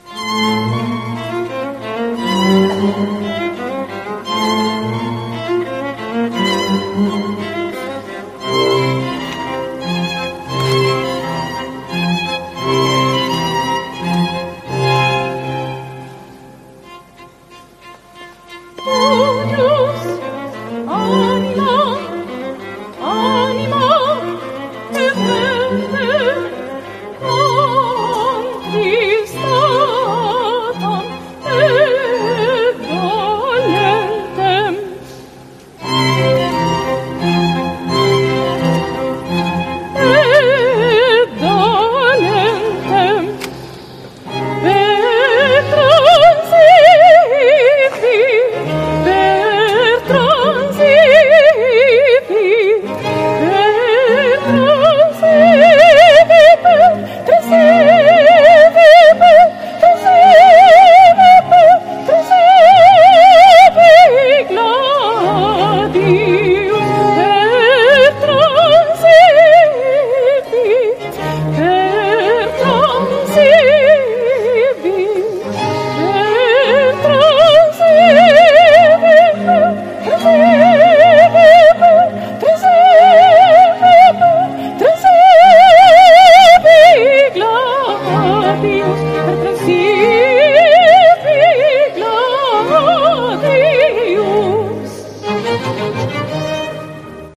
El Miserere en 2022 en la Catedral Nueva de Salamanca